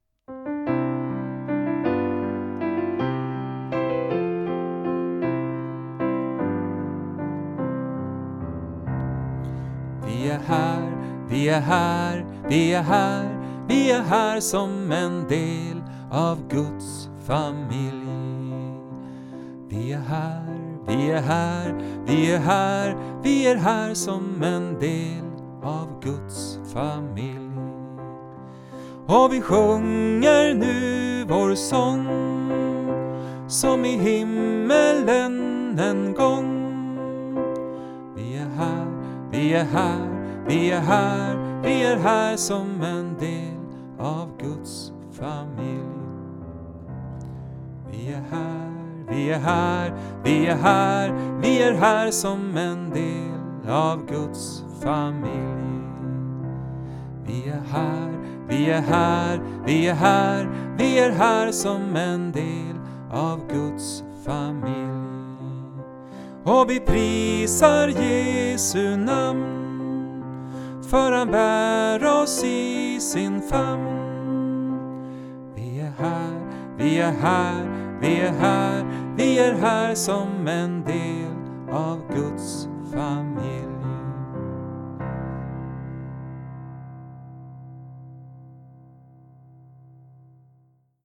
En samlingssång för barn, söndagsskolan m m